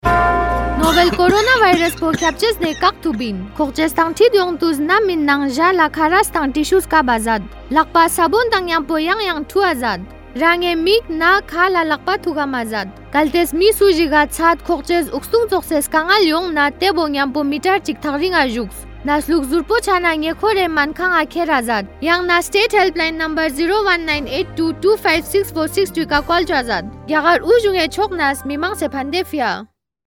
Radio PSA
5151_Cough Radio_Ladhaki.mp3